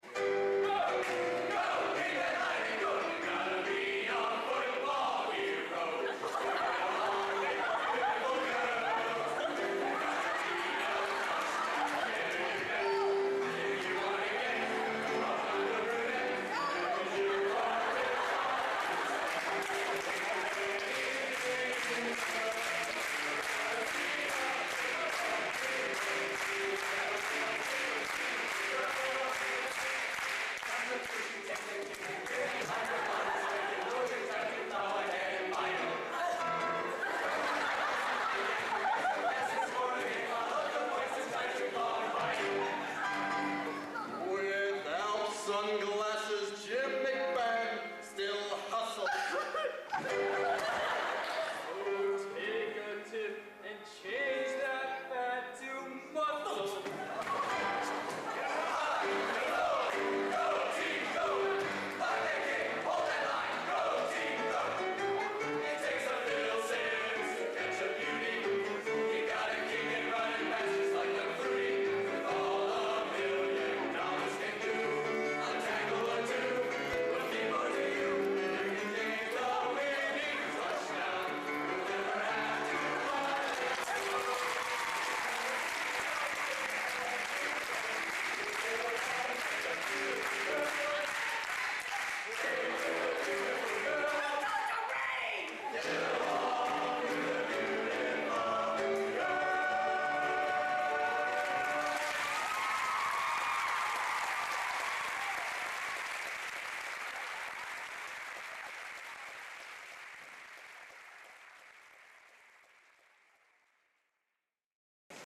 Location: Purdue Memorial Union, West Lafayette, Indiana
Genre: Collegiate | Type: